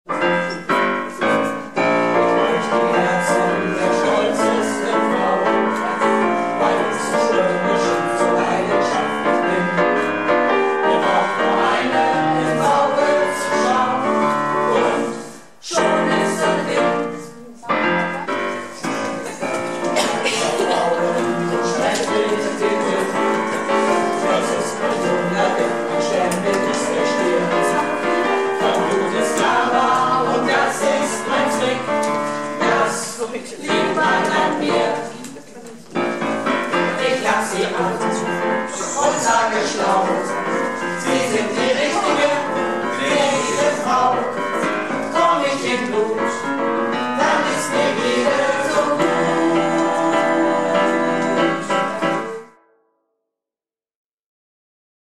Projektchor "Keine Wahl ist keine Wahl" - Probe am 26.03.19